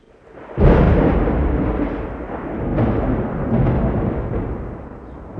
Tonnerre.wav